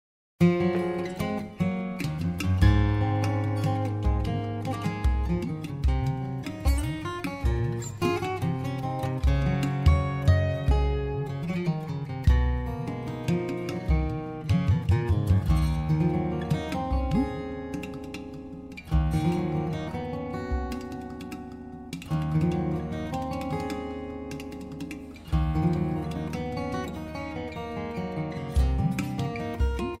a percussion instrument that sounds very similar to tablas